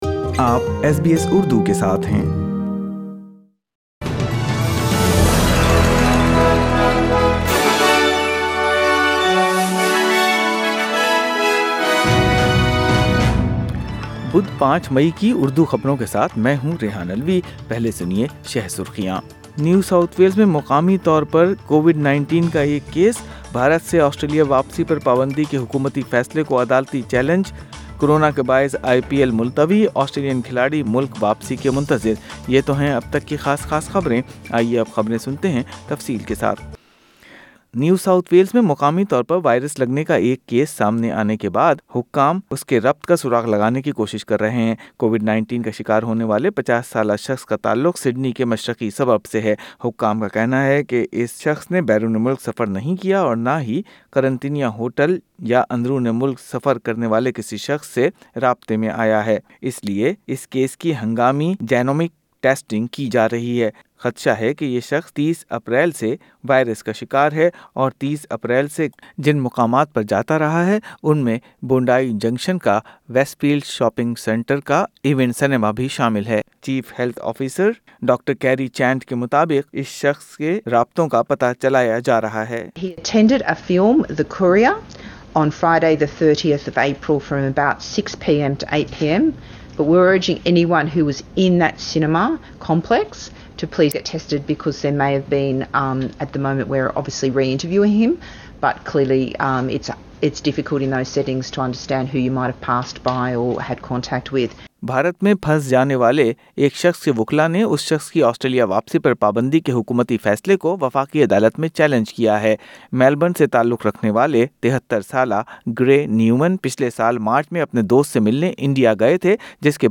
Urdu News Wed 5 May 2021